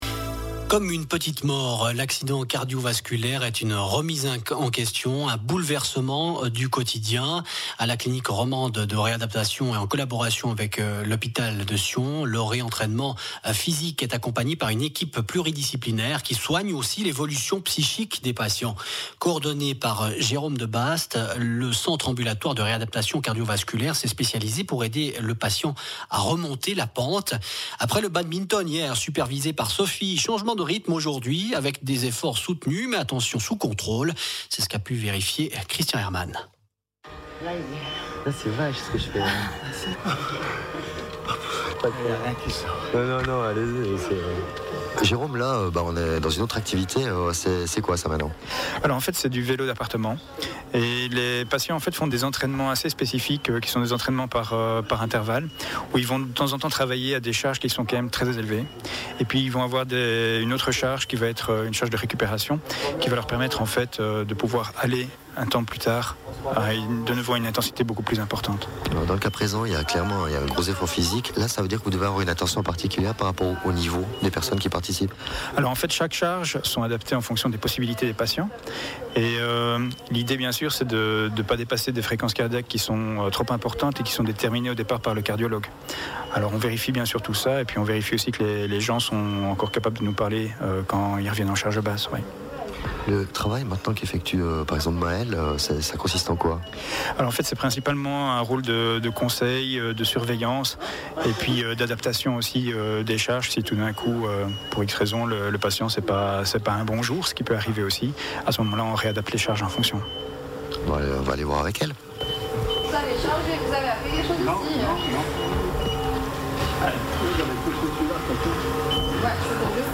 Im Rahmen der Sendung „ A la table de l’info“ hat Rhône FM eine Gruppe Patientinnen und Patienten bei ihrer kardiovaskulären Rehabiliation an der Clinique romande de réadaptation (CRR) begleitet und eine zweiteilige Reportage darüber realisiert.